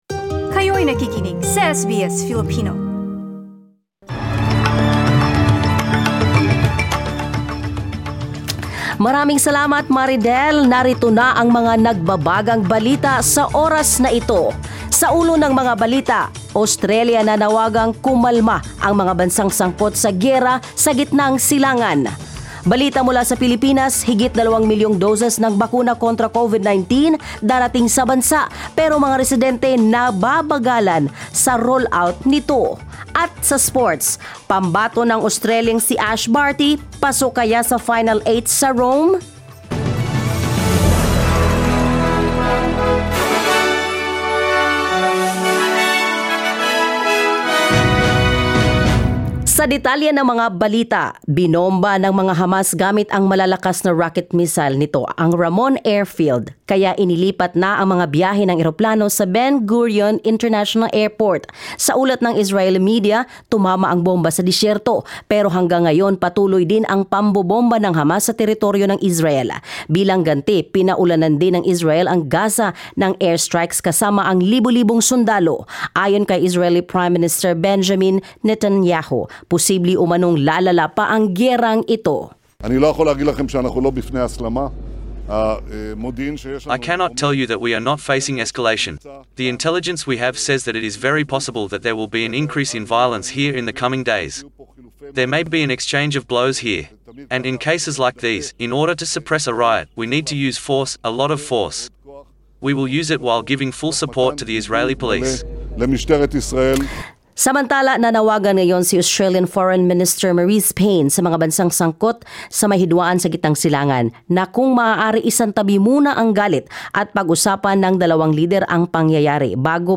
SBS News in Filipino, Friday 4 May